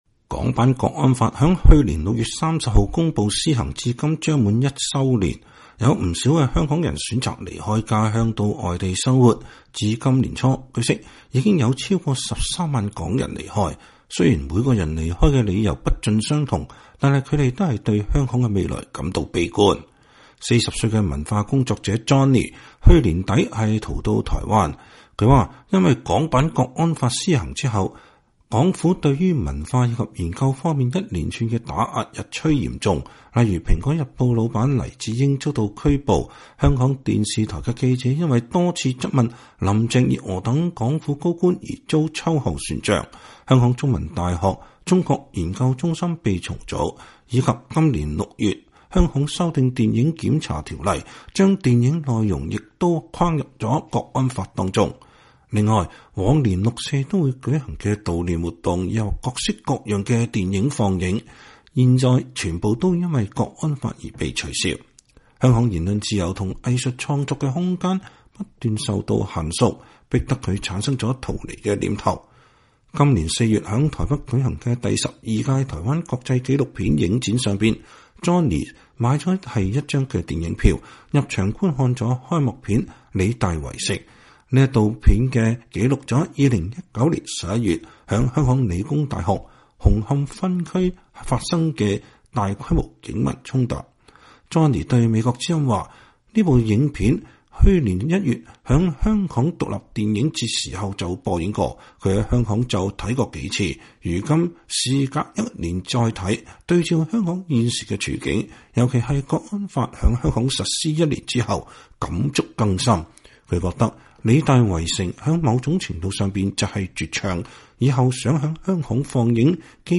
香港國安法即將施行一週年之際，美國之音採訪了多位離開香港到台灣生活的港人。他們說，港版國安法製造“白色恐怖”，形成寒蟬效應，香港變成了一個失去自由精神的地方，跟他們所認識的香港已經不一樣。